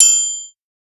triangle 1.wav